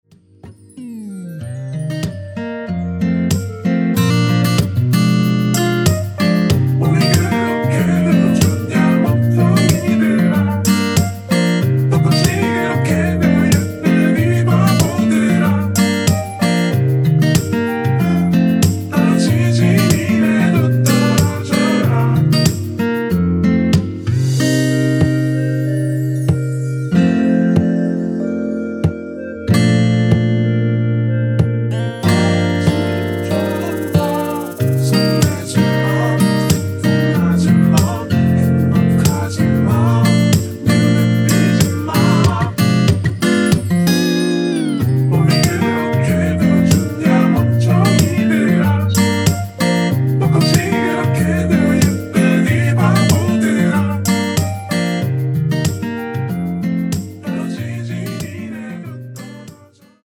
원키에서(-3)내린 멜로디와 코러스 포함된 MR 입니다.(미리듣기 확인)
Bb
앞부분30초, 뒷부분30초씩 편집해서 올려 드리고 있습니다.
중간에 음이 끈어지고 다시 나오는 이유는